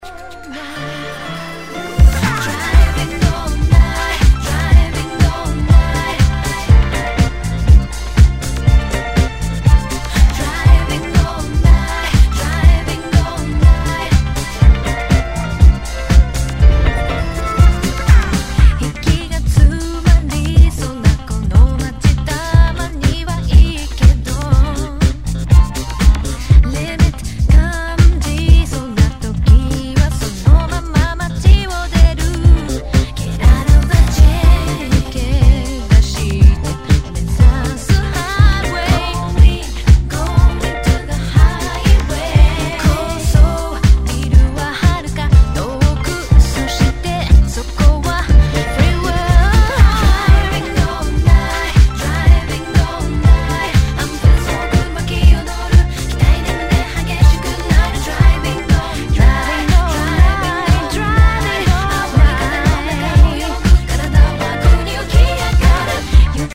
共にボサノバ・フレーバー溢れるまさに夏仕様のリミックス盤です!!
Tag       Japan R&B